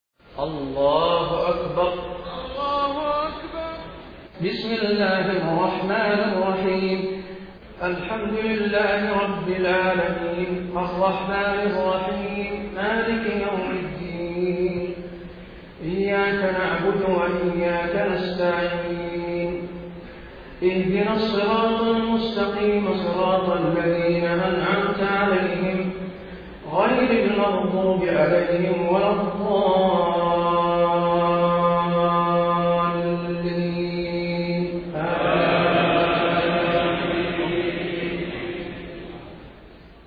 Recitations
taraweeh-1433-madina